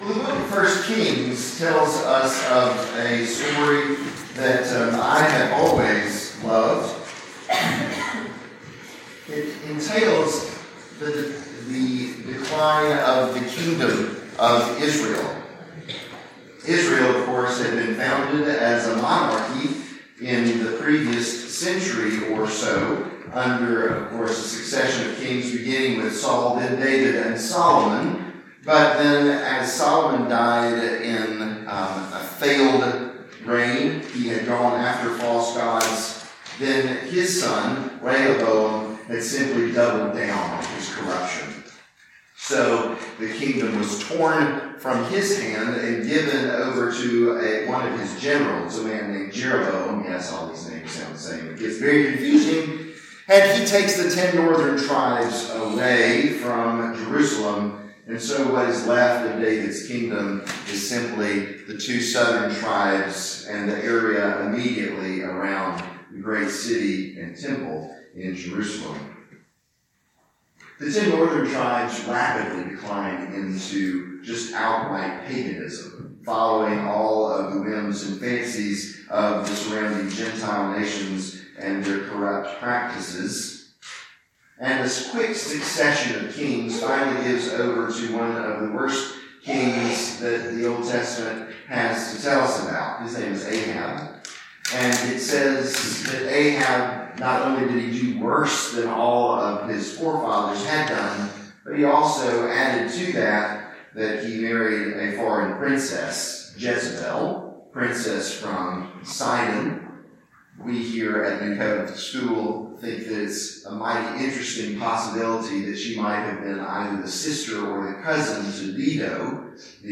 Sermons
All Saints Church, Lynchburg, Virginia